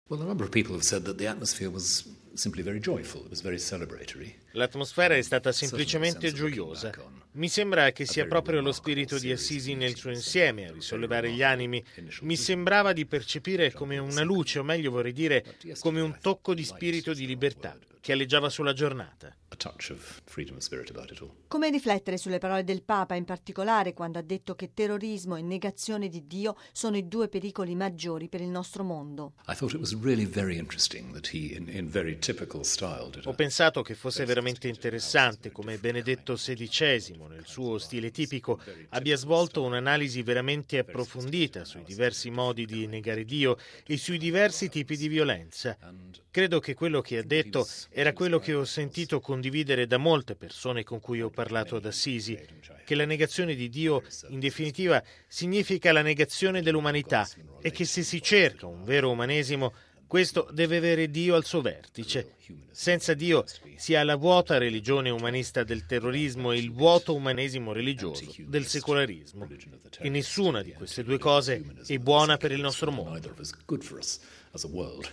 Tra i leader religiosi presenti c’era anche il primate della Comunione anglicana, l’arcivescovo di Canterbury Rowan Williams.